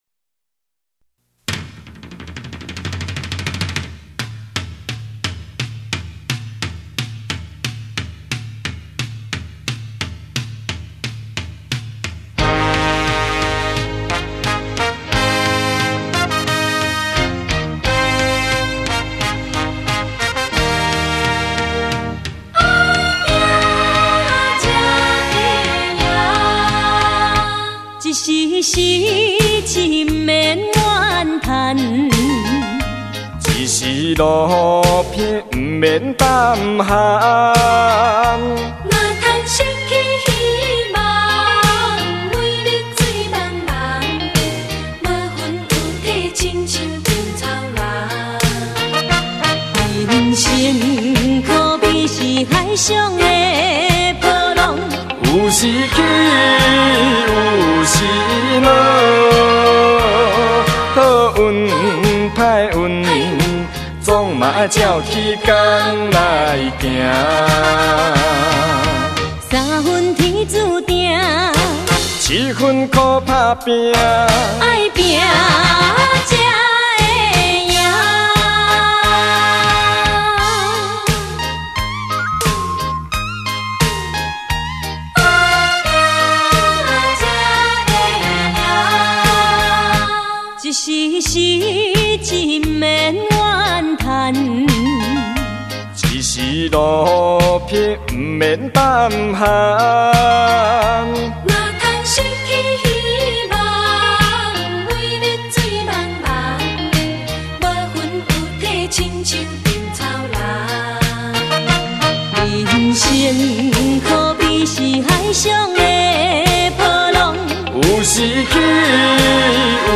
情歌对唱
台语KTV点唱
重新编曲 演唱